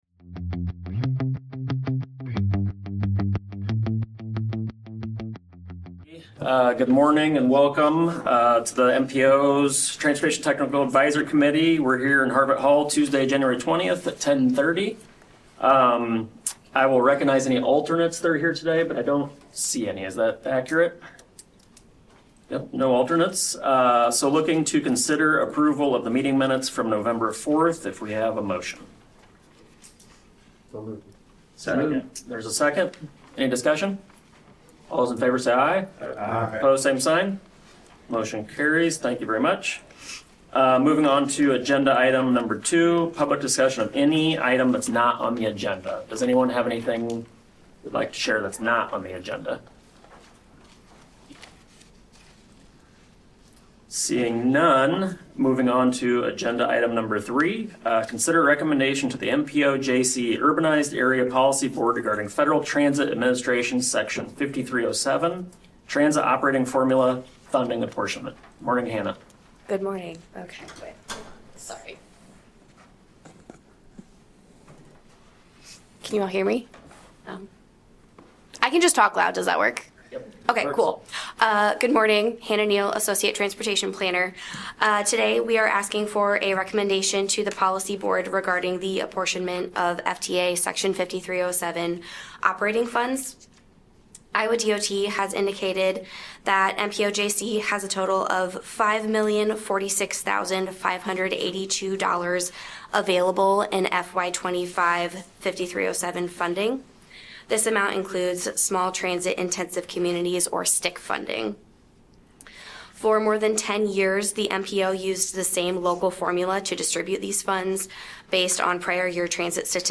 Meeting of the Metropolitan Planning Organization Urbanized Area Policy Board.